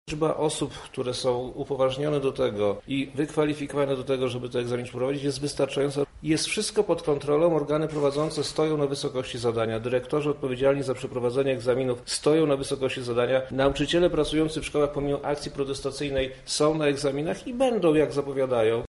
Wojewoda lubelski, Przemysław Czarnek zapewnia natomiast, ze dalsze egzaminy przebiegną w ten sam sposób: